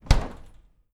DOOR_Fridge_Close_02_mono.wav